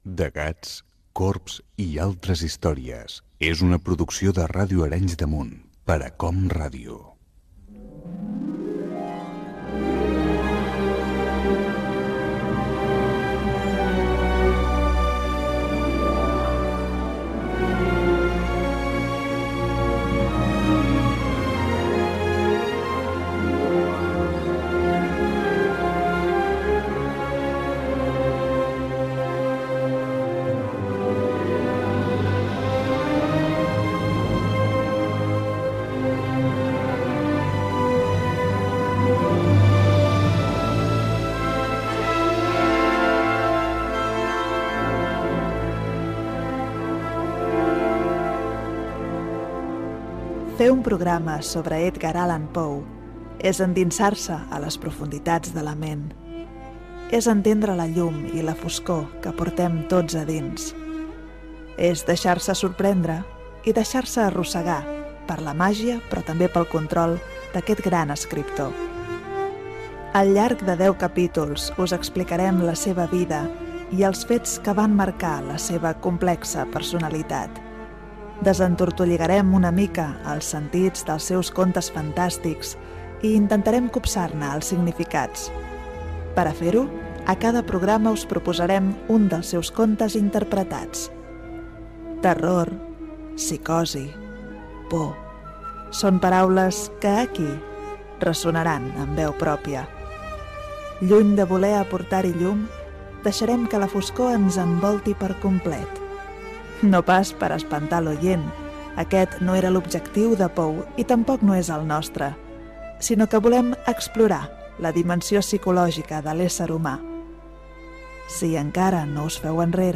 Careta del programa i presentació de la sèrie de programes d'estiu sobre l'escriptor Edgar Allan Poe.
Ficció